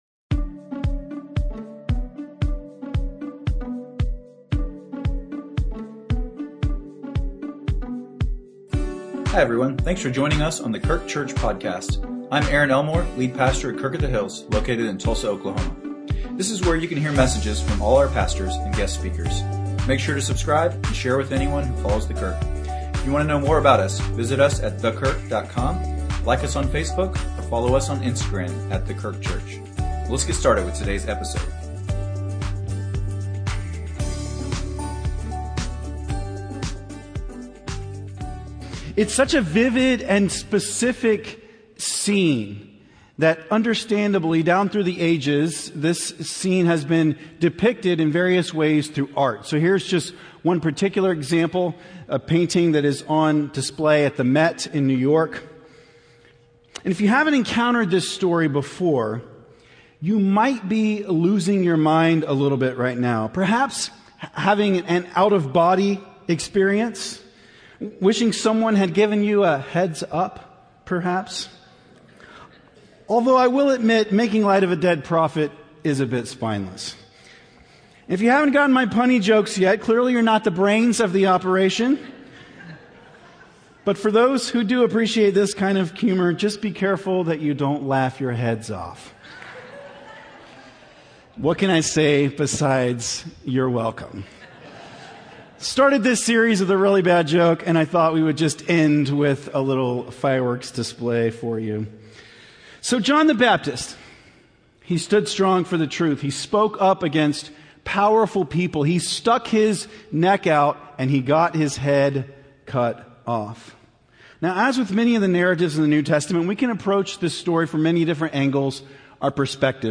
Sermons | The Kirk - Tulsa, OK